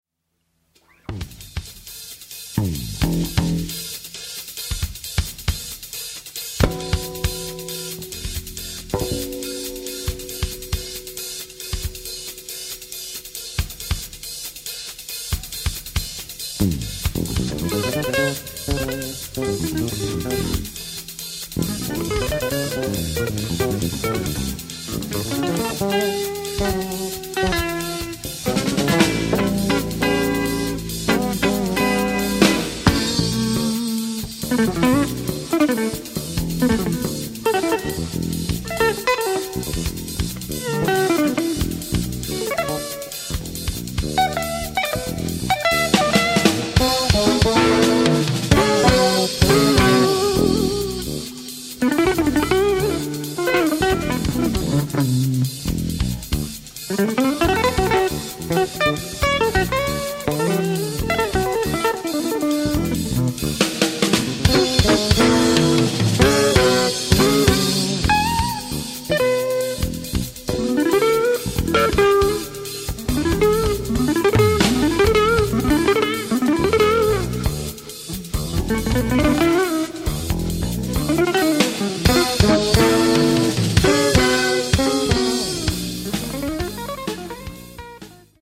recorded live at Frannz Club, Berlin